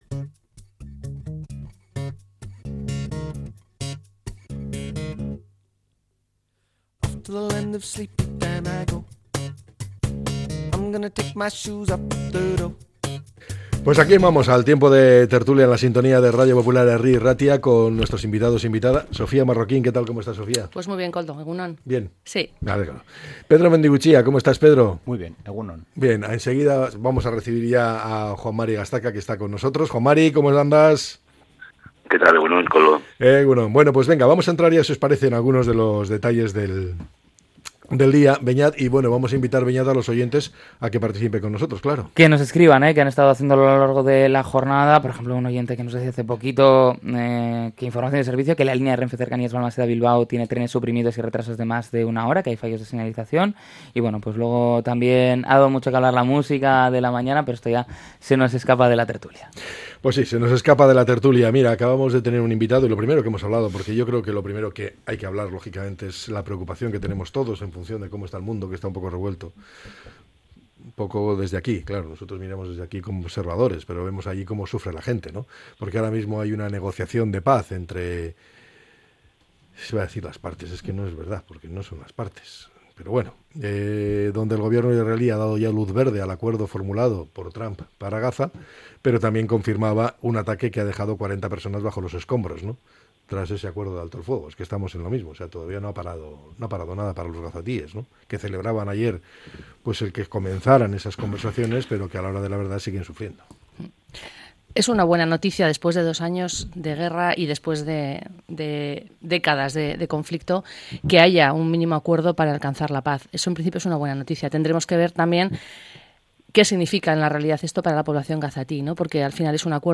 La tertulia 10-10-25.